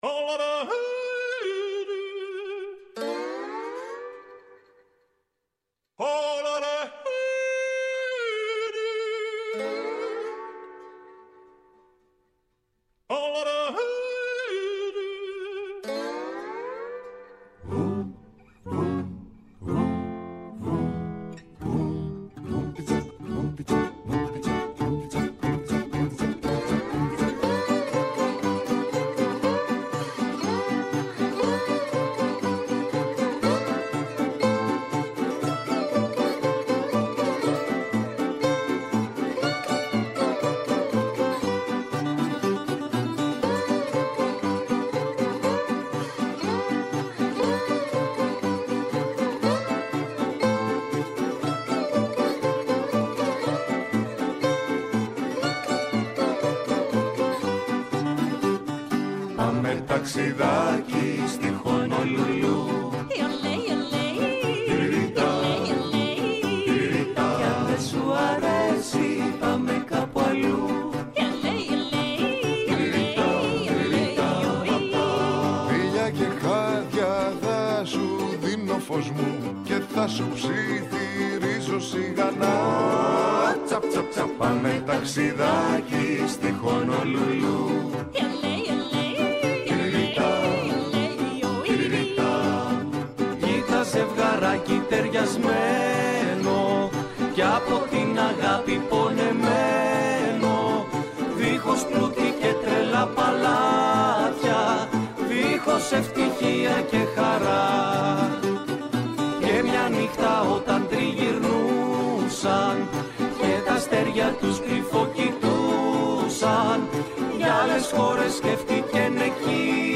Μια εκπομπή για το ντοκιμαντέρ και τους δημιουργούς του στο Α’ Πρόγραμμα της ΕΡΤ.